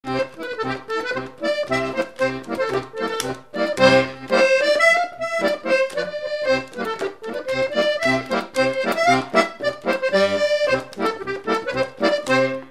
Note maraîchine
branle : courante, maraîchine
Pièce musicale inédite